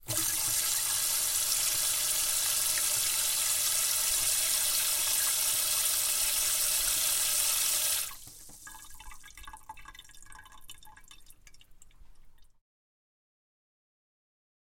家庭 " 01 自来水
描述：从水龙头中取水
Tag: 水槽 水龙头